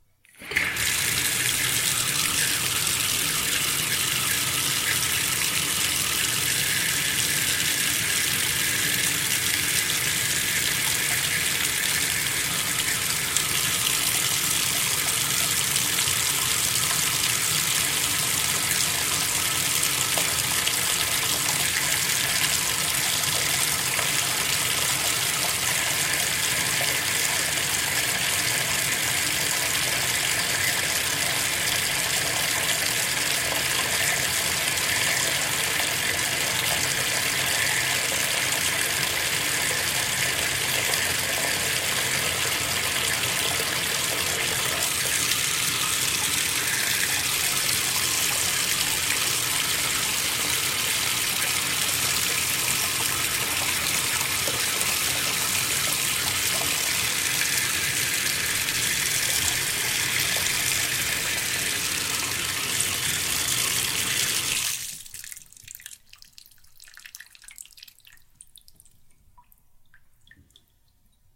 下水道
描述：一个浴缸的水龙头和排水口在运作
标签： 浴缸 排水
声道立体声